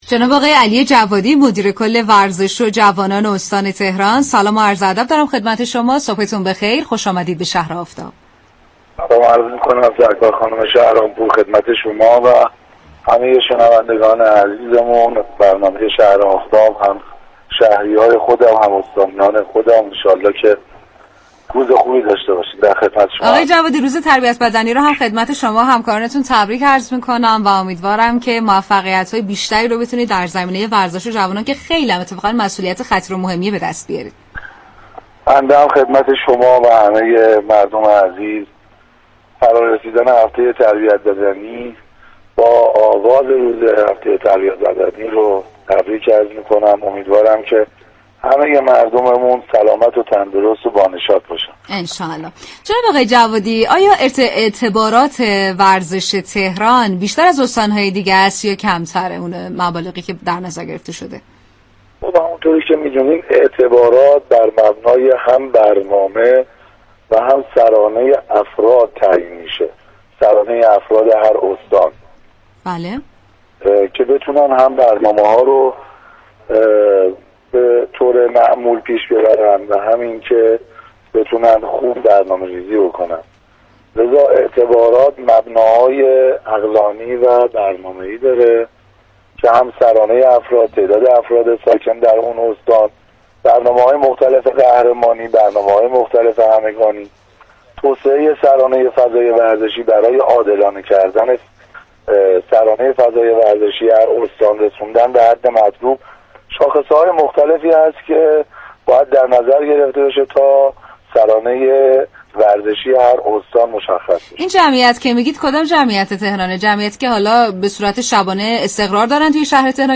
برنامه شهر آفتاب رادیو تهران روز سه شنبه 26 مهرماه در نخستین روز از هفته تربیت بدنی با علی جوادی مدیركل ورزش و جوانان استان تهران گفت و گو كرد.